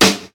• Old School Hip-Hop Snare Sound G Key 209.wav
Royality free snare sample tuned to the G note. Loudest frequency: 2996Hz
old-school-hip-hop-snare-sound-g-key-209-WVI.wav